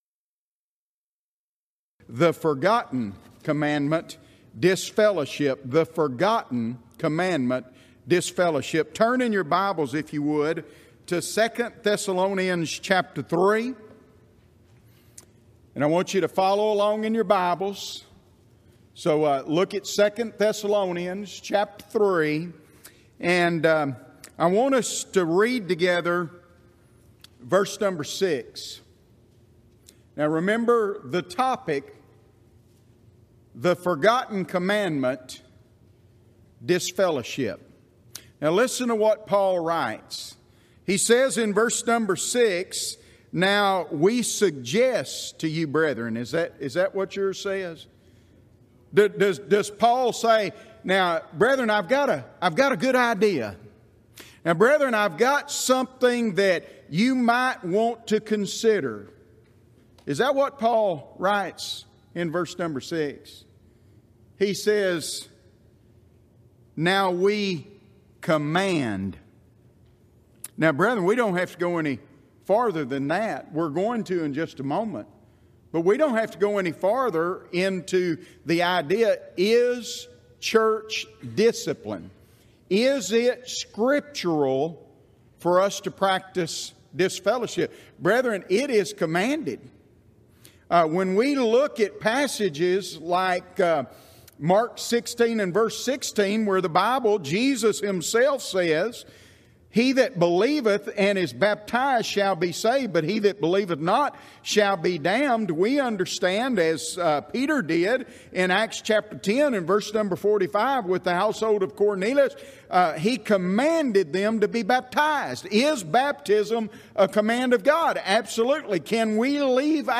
Event: 24th Annual Gulf Coast Lectures Theme/Title: Christian Fellowship
this lecture